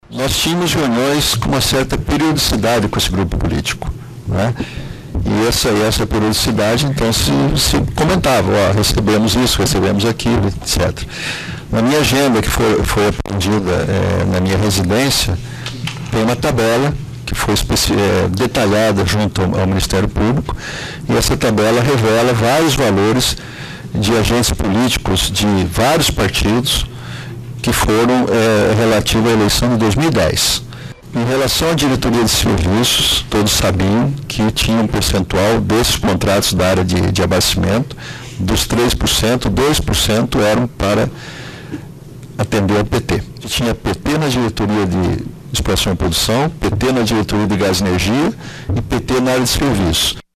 Depoimento de Youssef
Em áudio obtido pelo G1, Youssef diz ao juiz federal Sérgio Moro, responsável pelo processo da Lava Jato na primeira instância, que esses políticos trancaram a pauta do Congresso como uma forma de pressionar o ex-presidente.